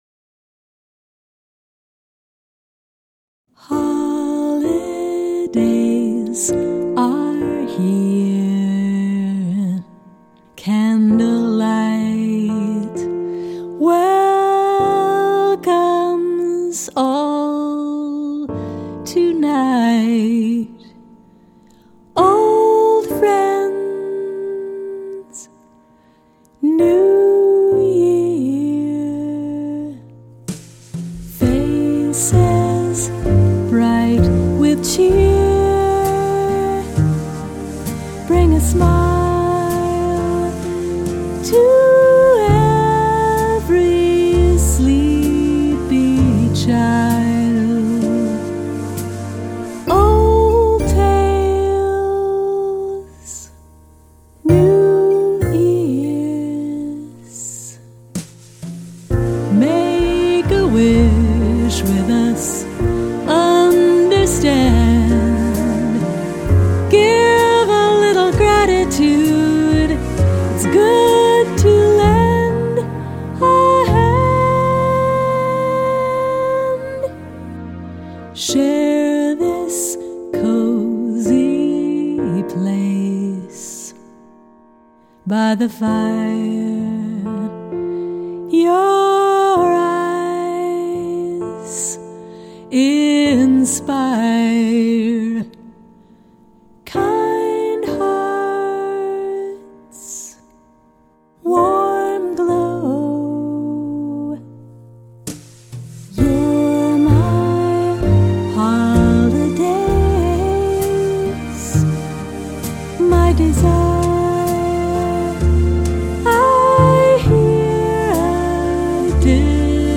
ballad
vocals